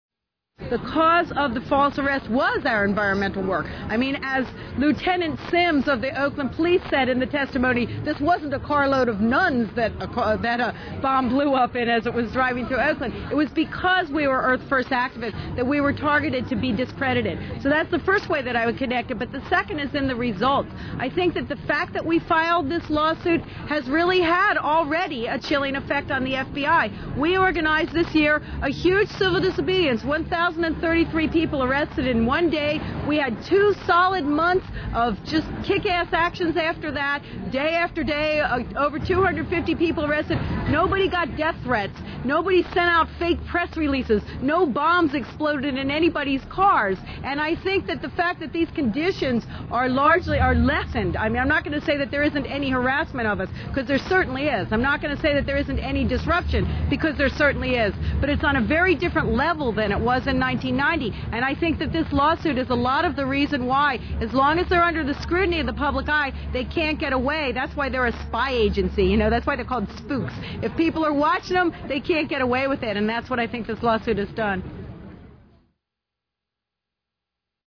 This is a compilation of recordings of Judi speaking at many different times and places, talking about her case and her beliefs on the radio and to interviewers, or speaking to thousands at the 1996 Rally for Headwaters.